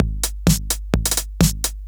Electrohouse Loop 128 BPM (22).wav